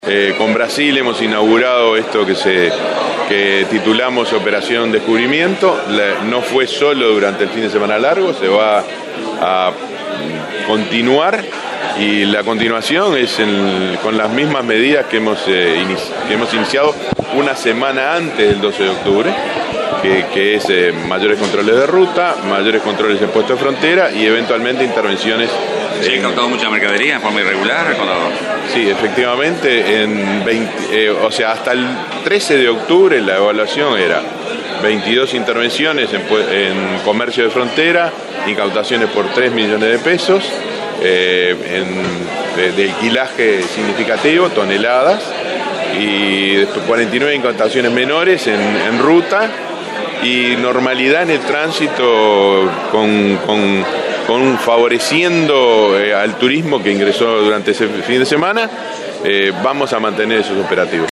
Su director nacional, Enrique Canon, dijo a Espectador al Día que fundamentalmente se han incautado productos comestibles en la frontera con Brasil.
Canon director de Aduanas